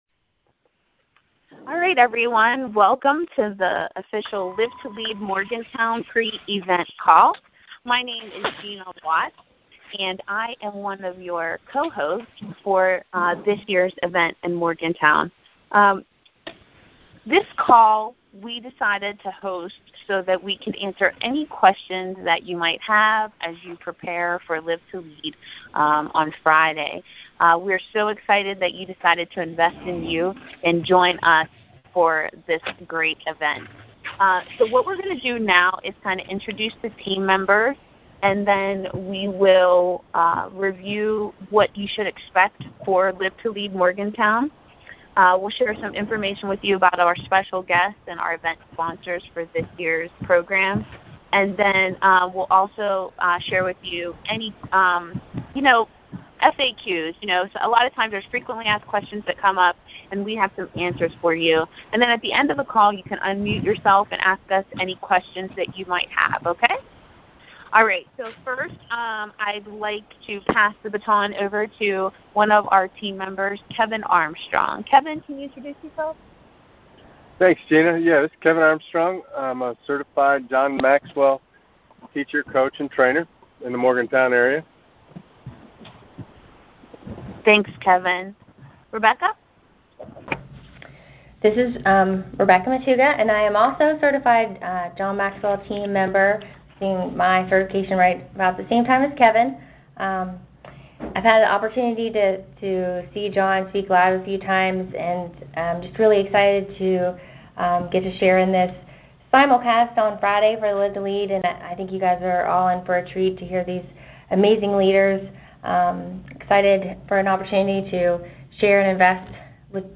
Thanks to everyone who joined us for our special pre-event call!